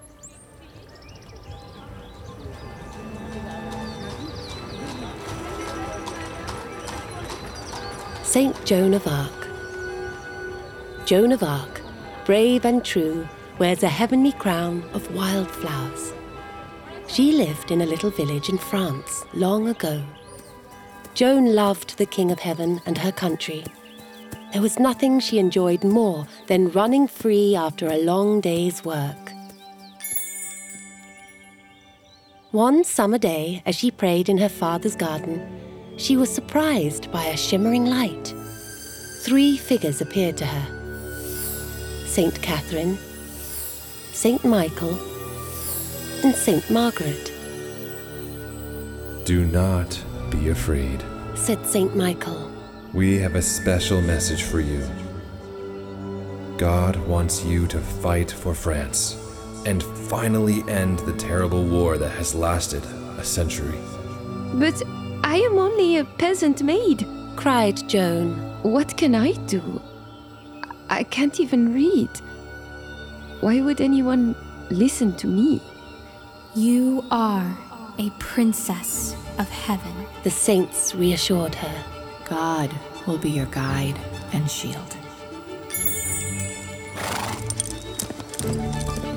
Voice Over Services British Narration and Commercial Voice Work
From children’s fiction to epic fantasy or memoirs, I provide engaging narration with pacing, characterisation, and warmth tailored to your audience.